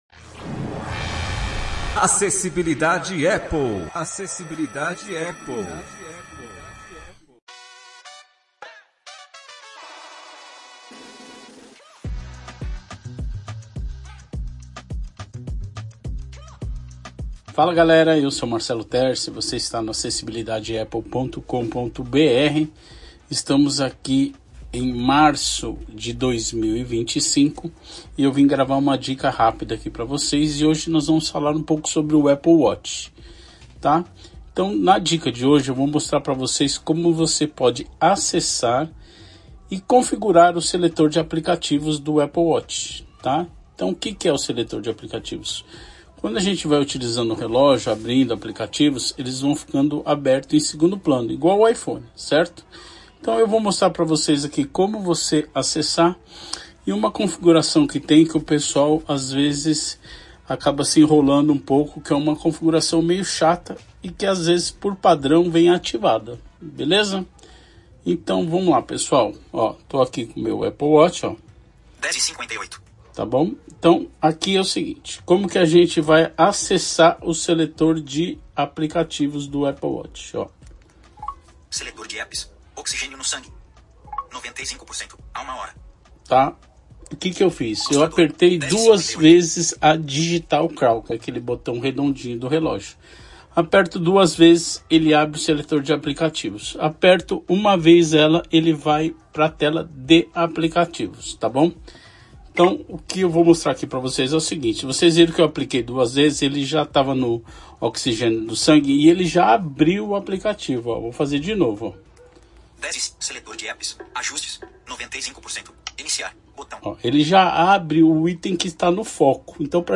Áudio tutorial